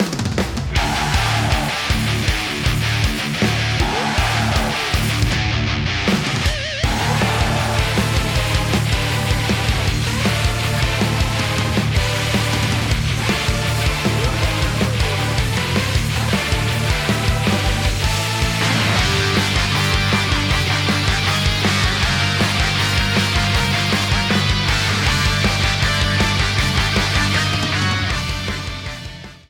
Ripped from the game
faded out the last two seconds